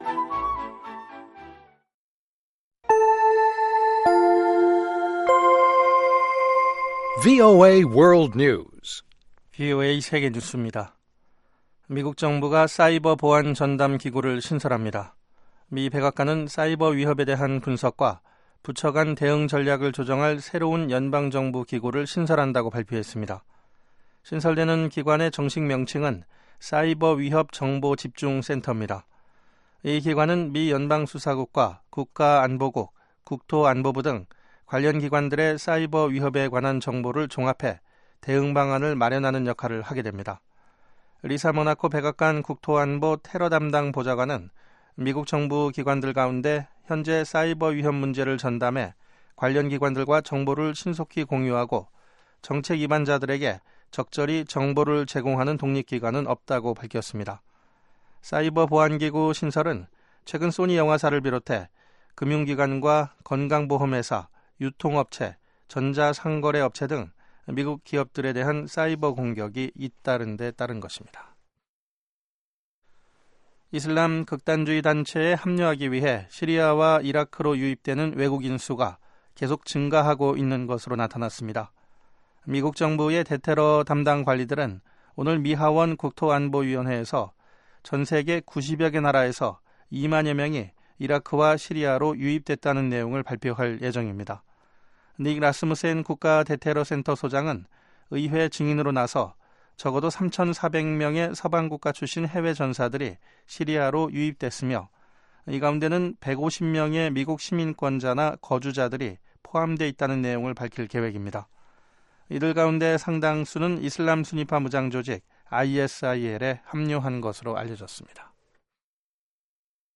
VOA 한국어 방송의 시사 교양 프로그램입니다.